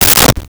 Medicine Cabinet Door Close
Medicine Cabinet Door Close.wav